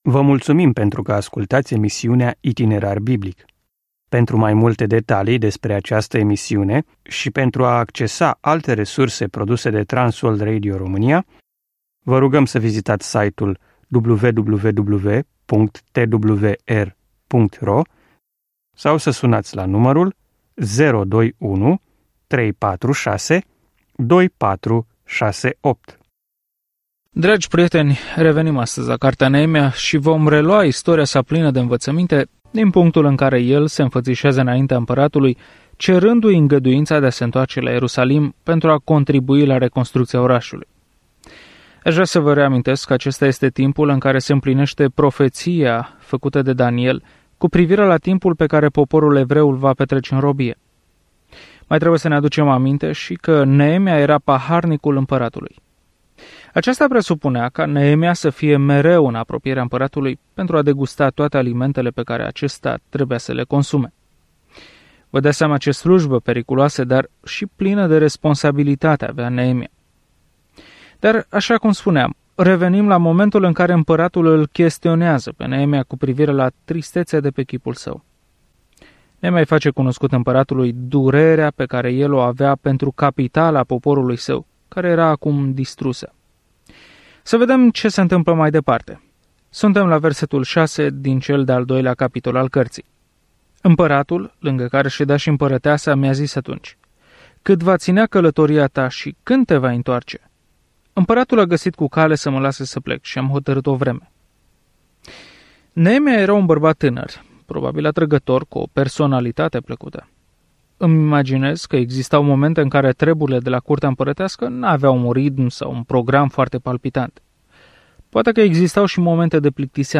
Scriptura Neemia 2:6-20 Neemia 3:1-2 Ziua 1 Începe acest plan Ziua 3 Despre acest plan Când Israel se întoarce în țara lor, Ierusalimul este într-o stare proastă; un om obișnuit, Neemia, reconstruiește zidul din jurul orașului în această ultimă carte istorică. Călătoriți zilnic prin Neemia în timp ce ascultați studiul audio și citiți versete selectate din Cuvântul lui Dumnezeu.